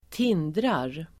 Uttal: [²t'in:drar]